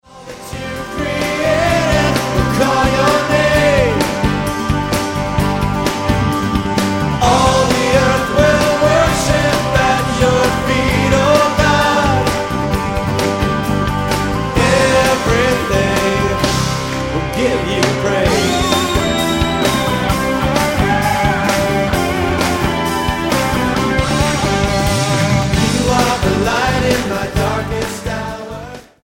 STYLE: MOR / Soft Pop
The musical arrangements are slick, but not too much so.